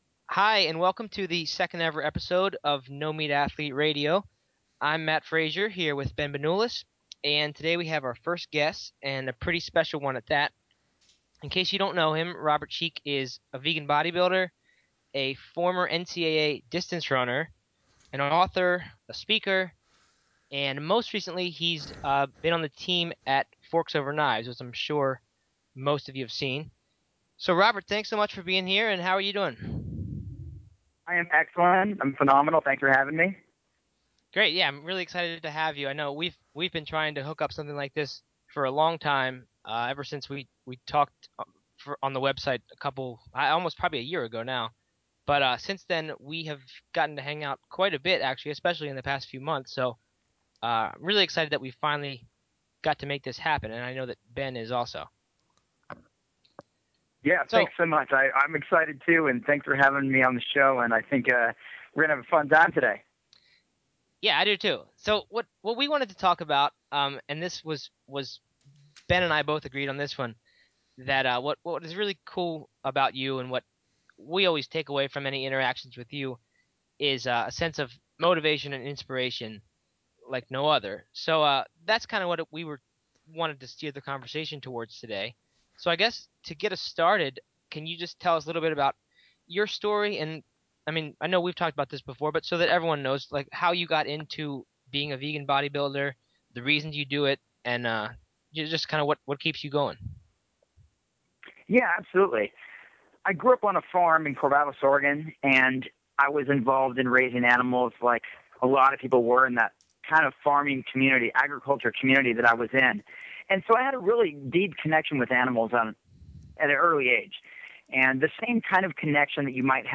So why a vegan bodybuilder for our first guest, when for so long this website has focused largely on running?